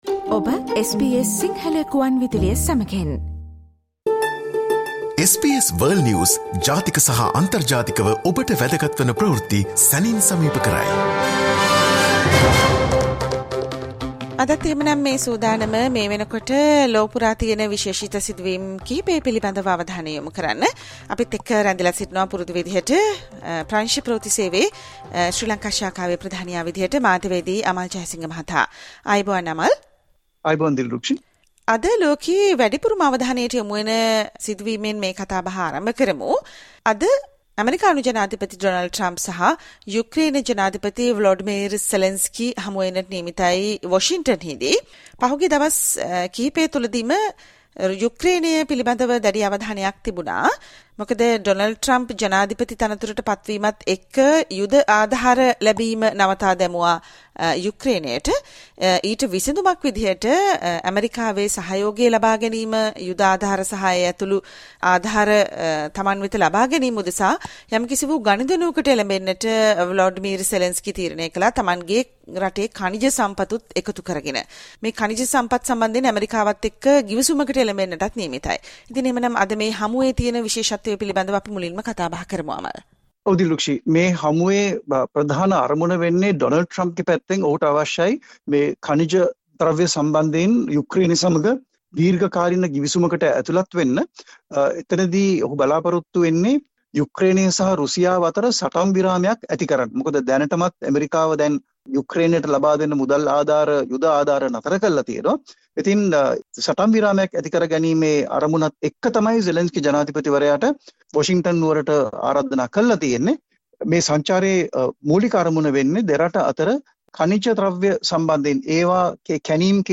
world news highlights